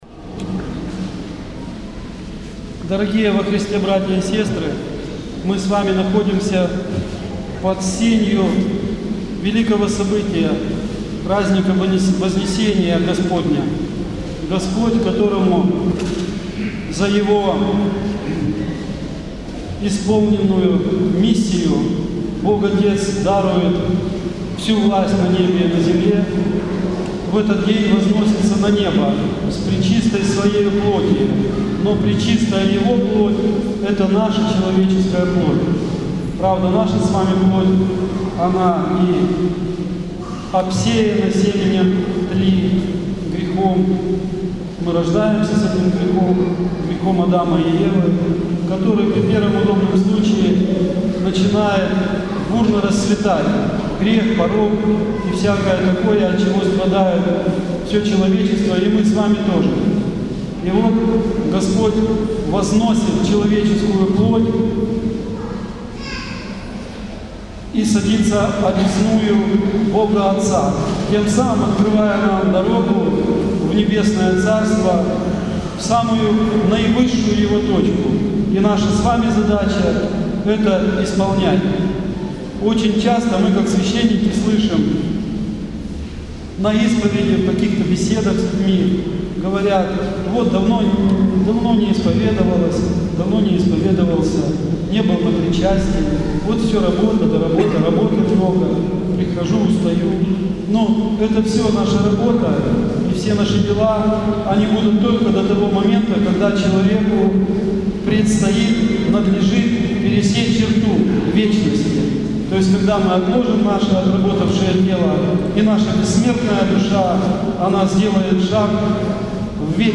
Проповедь в 7-ю неделю по Пасхе, святых отцов I Вселенского собора.